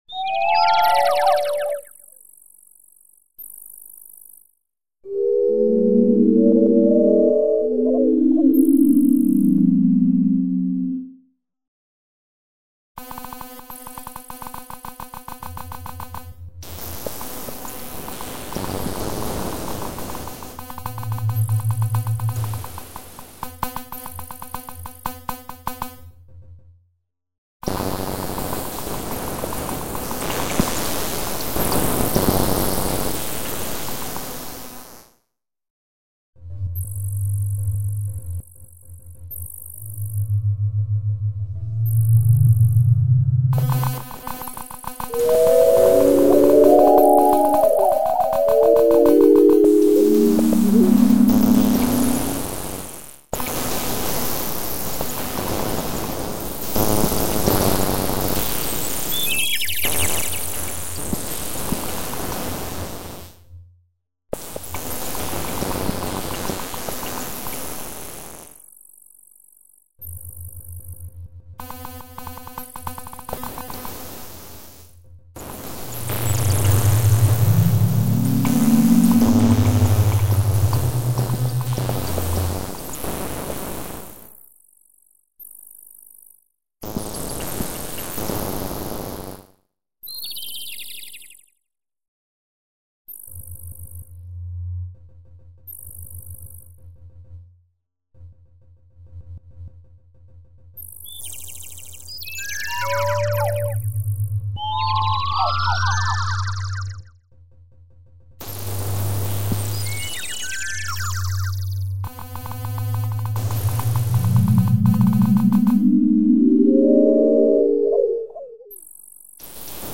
zvočnega eksperimenta